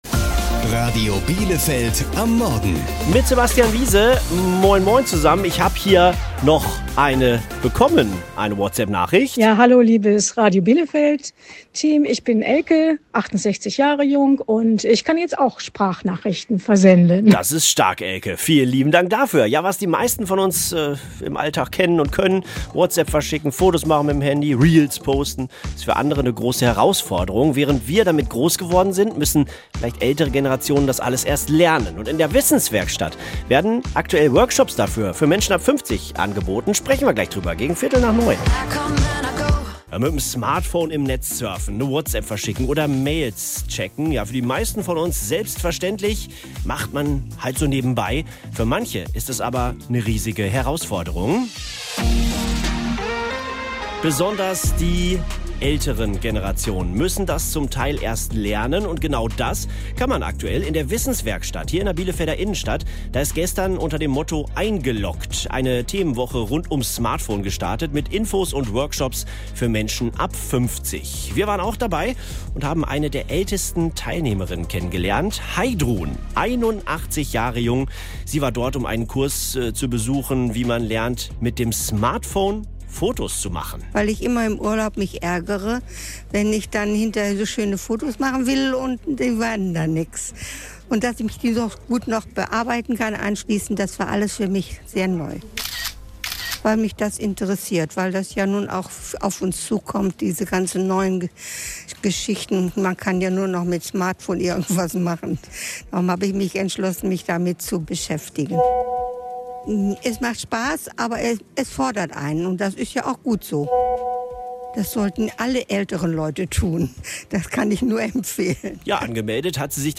Teilnehmer*innen im Interview mit Radio Bielefeld